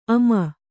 ♪ ama